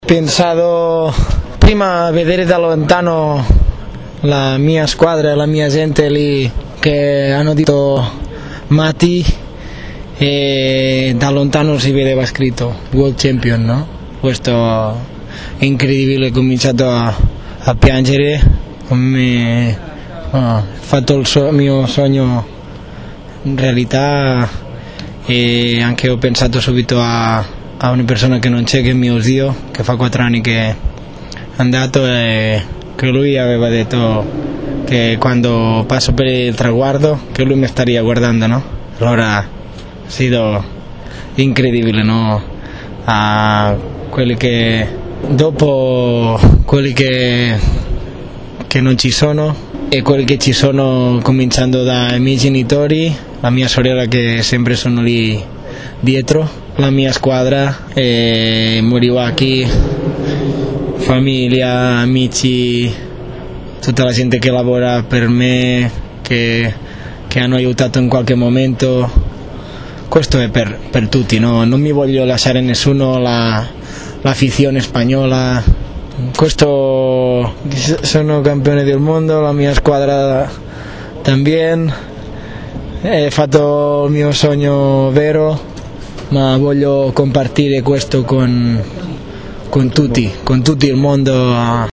Ascolta l'audio integrale dell'intervista a Toni Elias